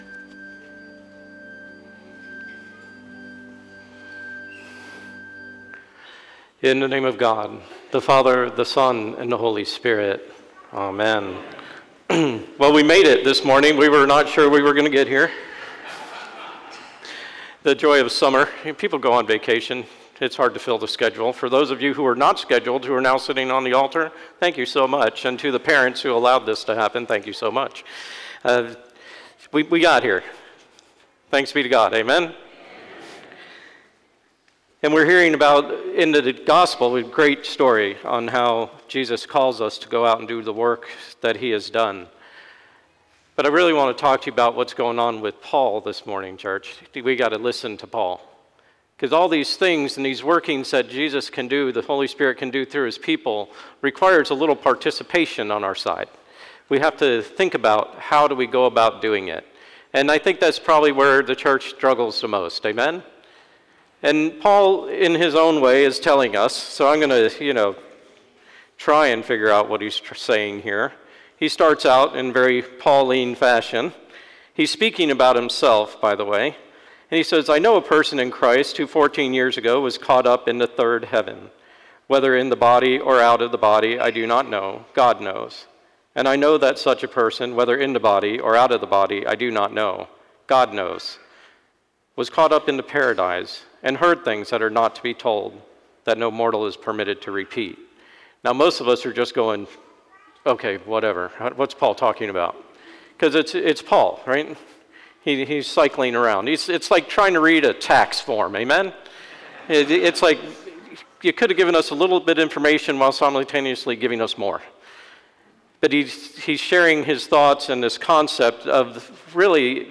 Sermon 7/07/24 Seventh Sunday after Pentecost - Holy Innocents' Episcopal Church
Sermon 7/07/24 Seventh Sunday after Pentecost